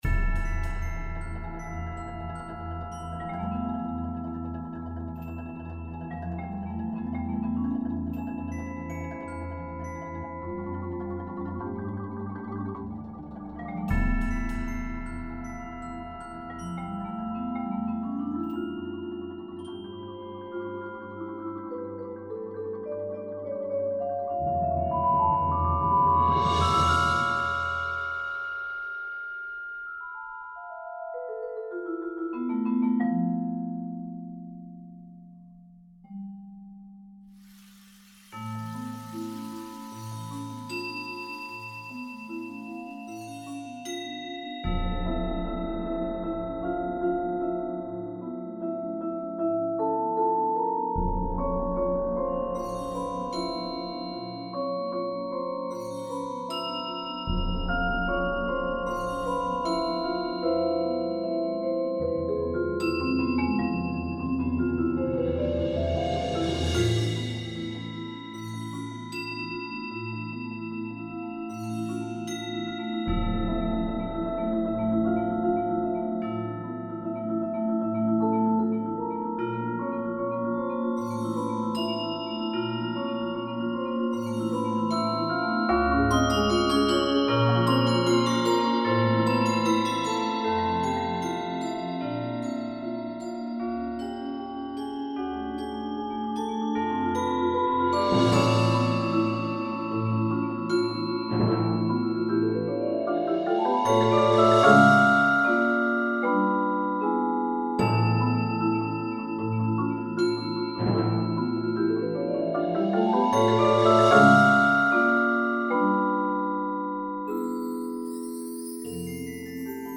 Gattung: Solo für Vibraphon und Blasorchester
Besetzung: Blasorchester